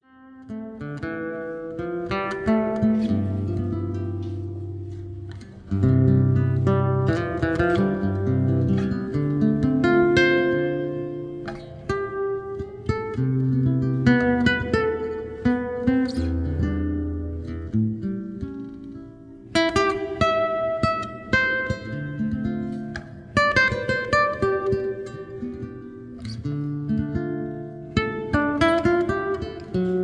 Solo Guitar Standards
Live in Little Tokyo
Soothing and Relaxing Guitar Music